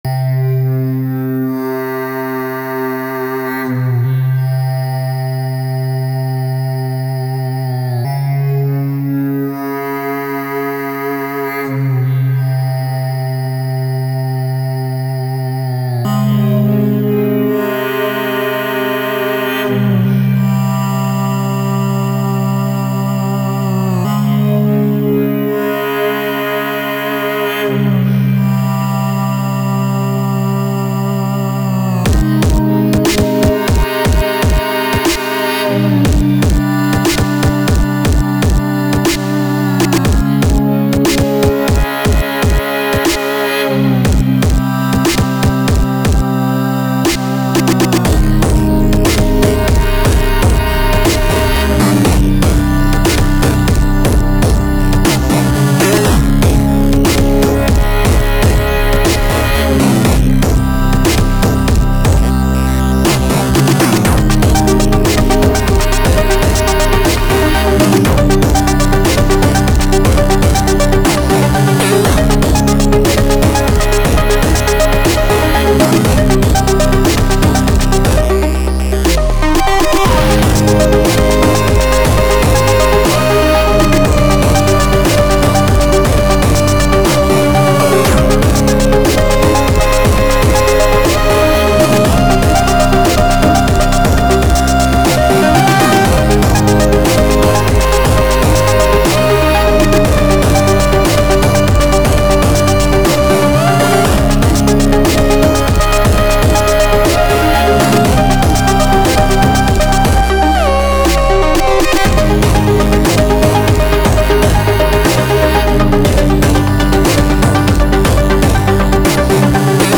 Kompo Musiikki Alusta dirtywave m8 tracker headless, can run the song live on stage. Sijoitus 1. sija Pisteet 17,63 Kuuntele Lataa tiedosto Lähdekoodi Dirtywave M8 tracker, part sampler part synth stuff all running on a teensy4.1. Can be executed live if preferred, needs hdmi from the stage